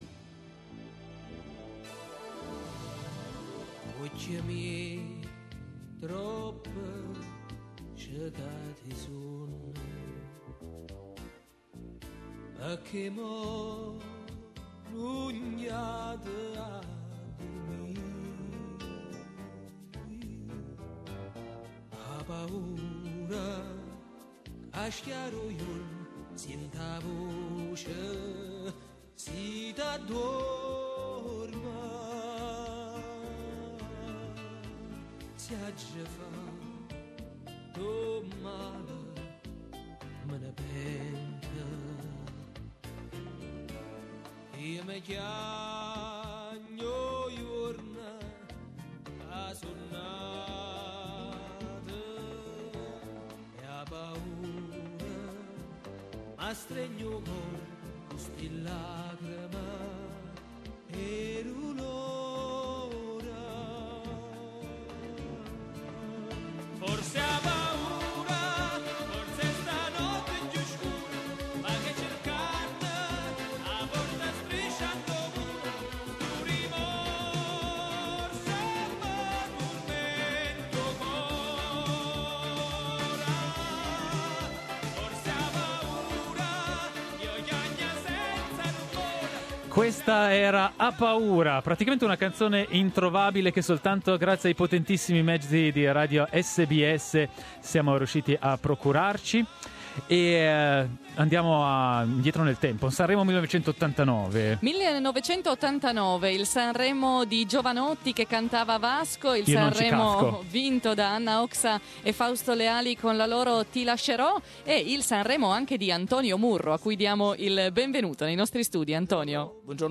Instant classic at the SBS studios:
Il cantante e chitarrista napoletano